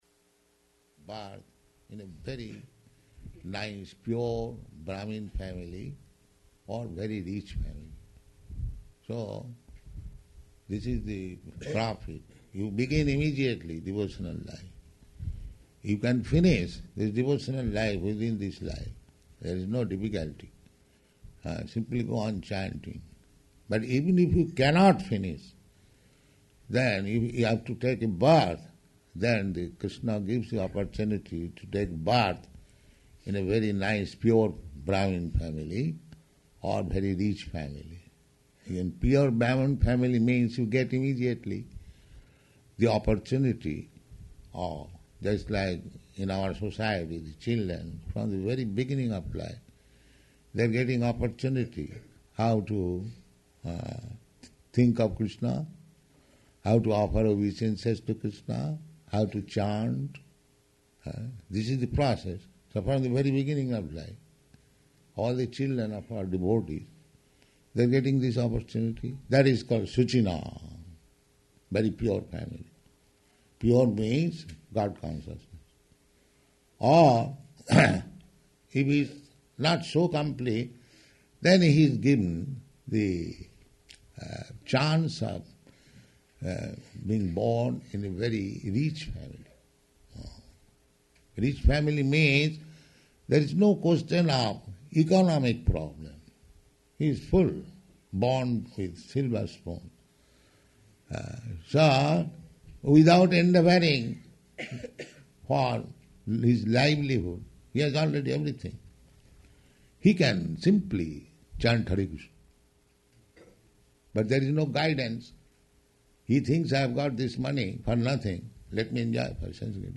Śrīmad-Bhāgavatam [partially recorded]